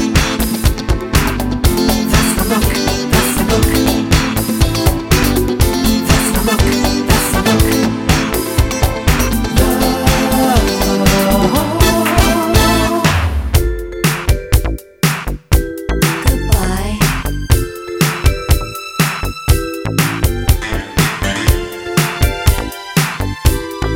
No Guitars Pop (1980s) 3:28 Buy £1.50